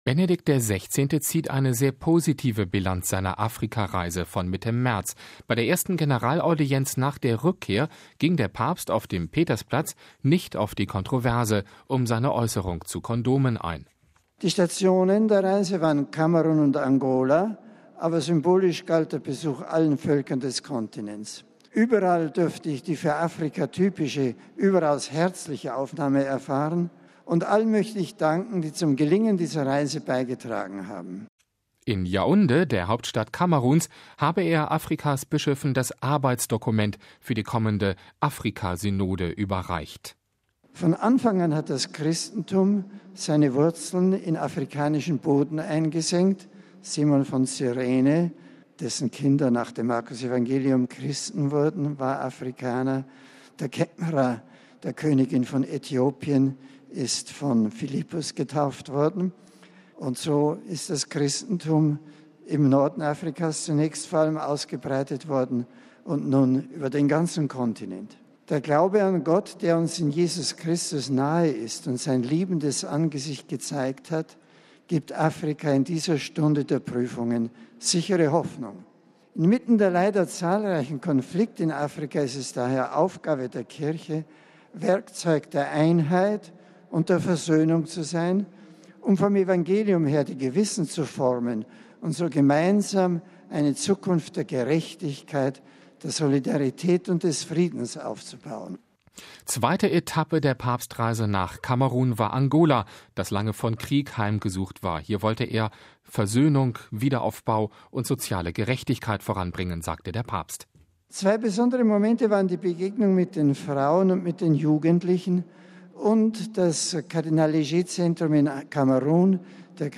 Bei seiner ersten Generalaudienz nach der Rückkehr ging der Papst auf dem Petersplatz nicht auf die Kontroverse um seine Äußerung zu Kondomen ein.